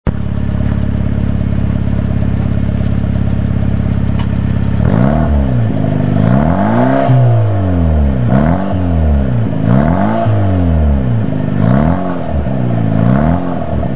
アイドリングでは、純正マフラーよりも若干低音が増した感じ。
3000rpmあたりが、騒音のピークでしょう。
サウンド 　　アイドル〜3000rpm (100KB 20sec mp3)